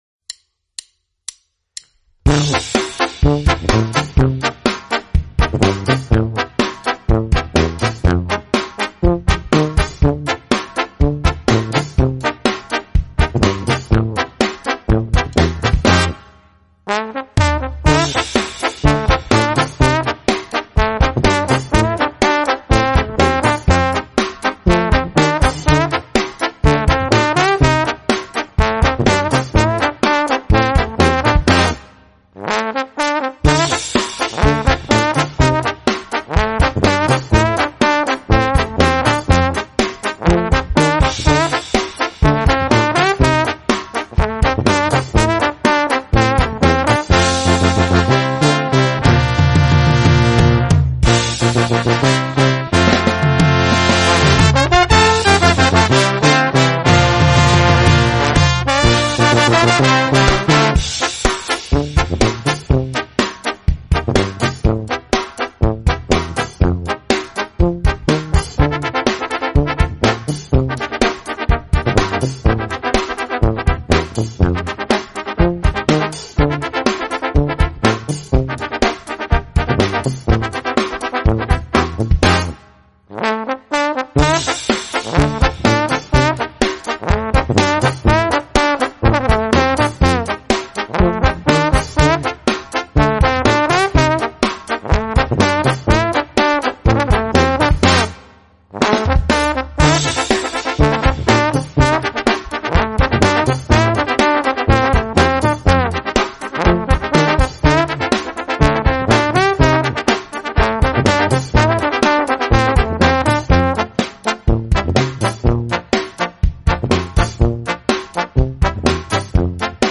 Gattung: Kleine Besetzung
Besetzung: Kleine Blasmusik-Besetzung